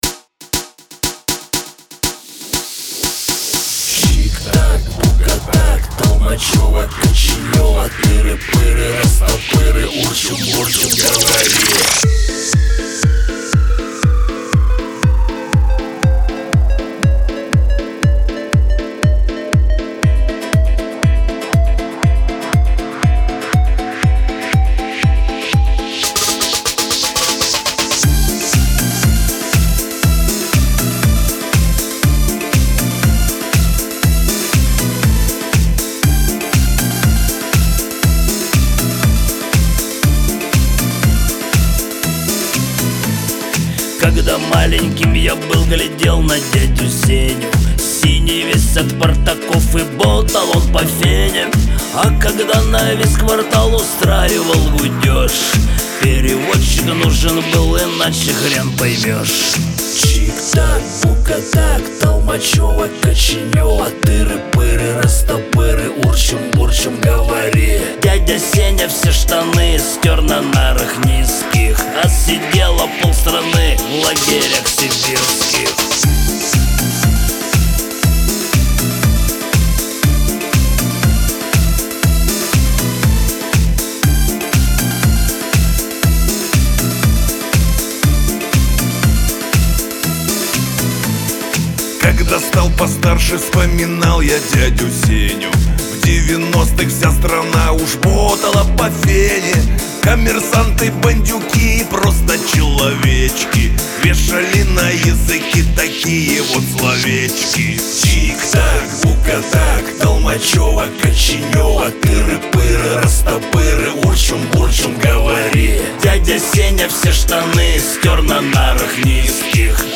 Лирика
Шансон
Веселая музыка